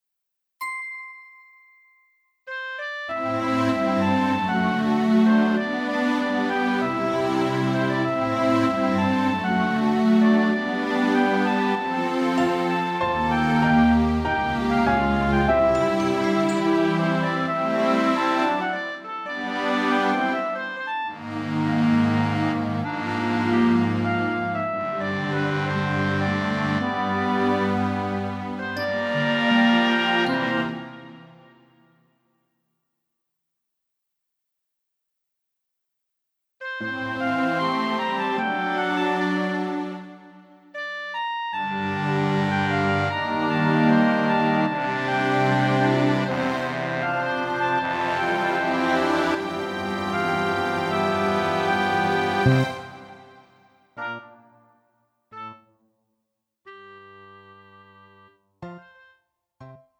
음정 원키 3:21
장르 가요 구분 Pro MR